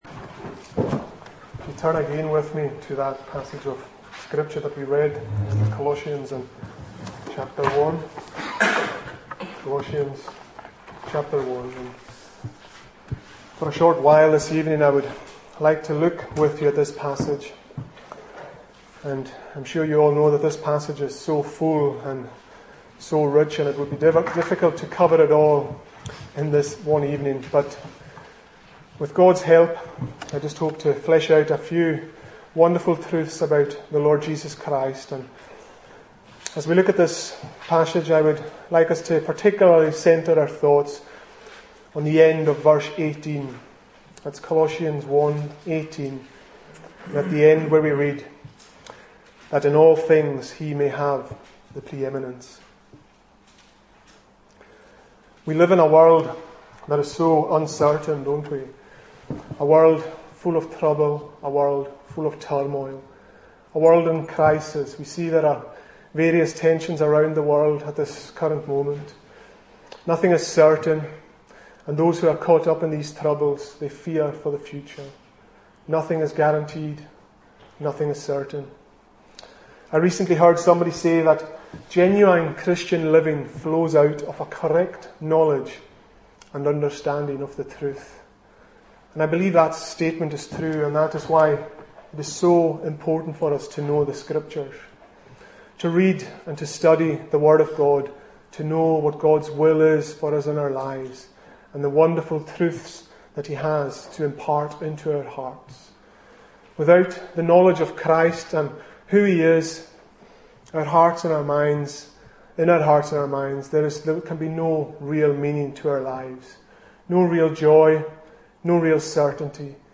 Single Sermons Book